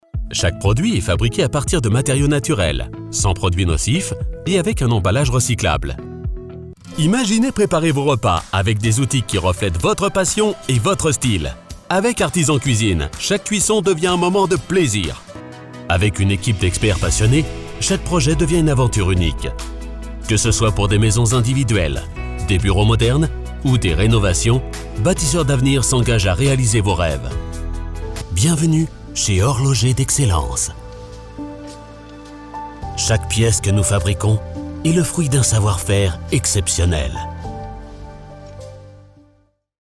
Flexible from sensual to very energetic. Clear, warm voice.